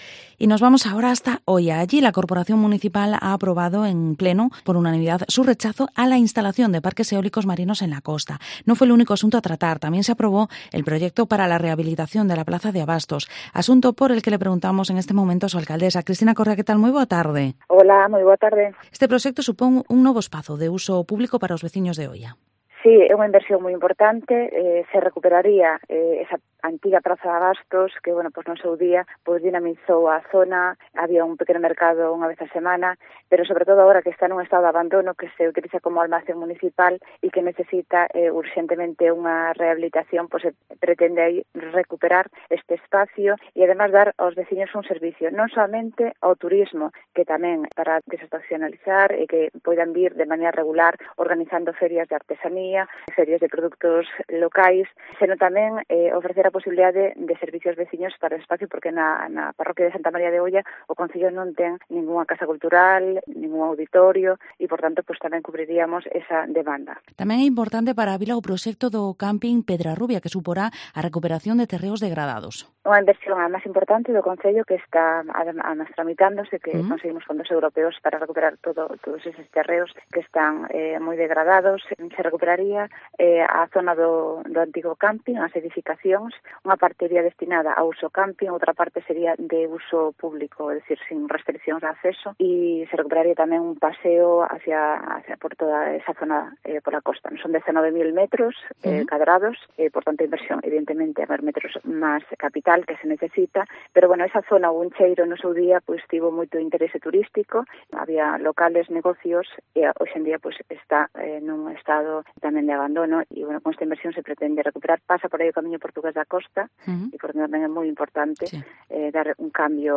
Entrevista a la Alcaldesa de Oia, Cristina Correa